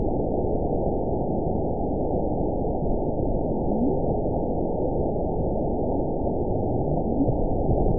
event 919545 date 01/10/24 time 17:22:01 GMT (1 year, 5 months ago) score 7.26 location TSS-AB08 detected by nrw target species NRW annotations +NRW Spectrogram: Frequency (kHz) vs. Time (s) audio not available .wav